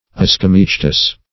As`co*my*ce"tous, a.